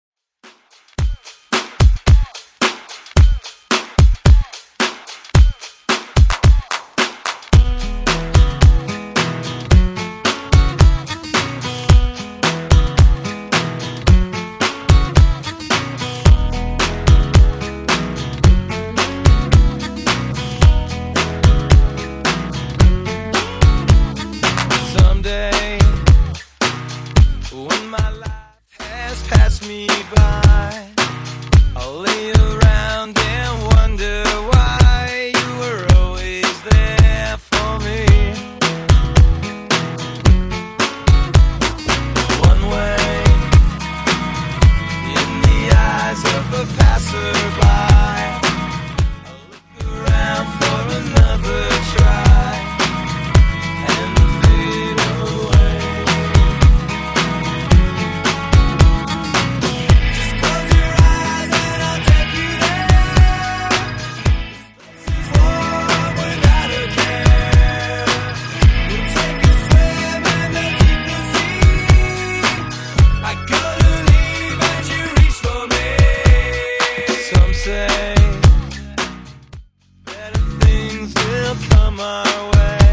Genre: 80's Version: Clean BPM: 119